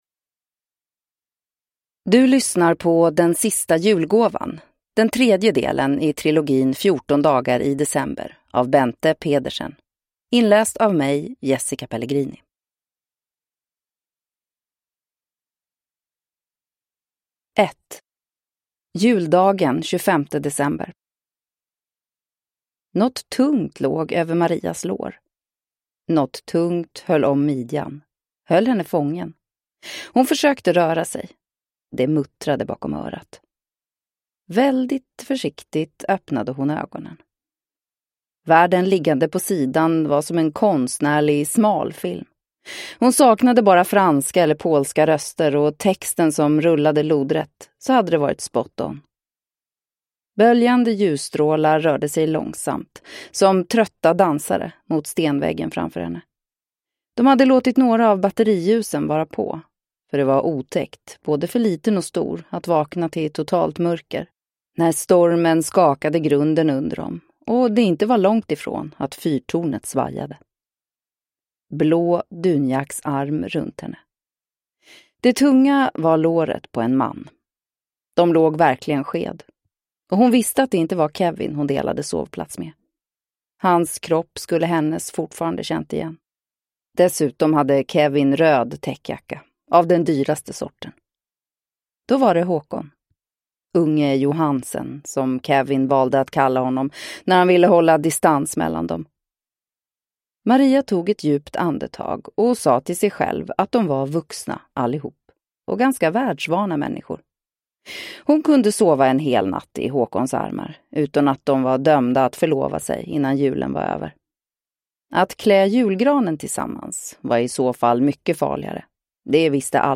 Den sista julgåvan (ljudbok) av Bente Pedersen